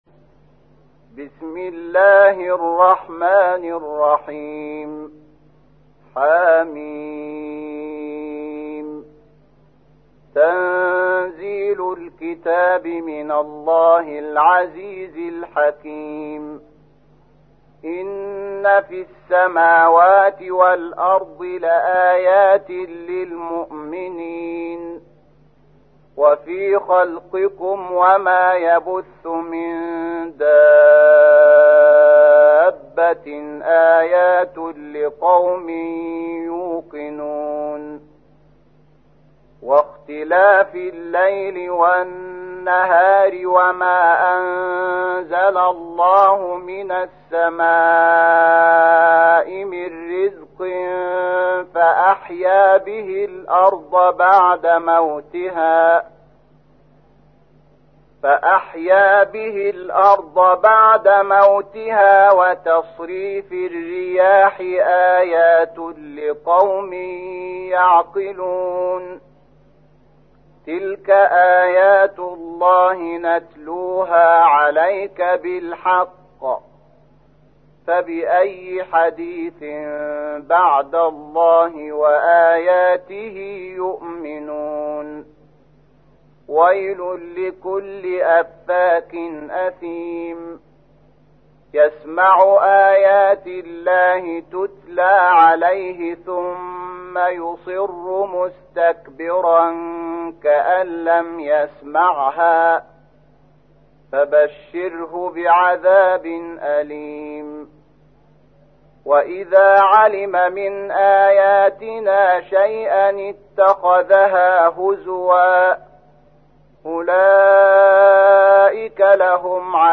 تحميل : 45. سورة الجاثية / القارئ شحات محمد انور / القرآن الكريم / موقع يا حسين